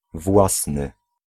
Ääntäminen
IPA: /pʁɔpʁ/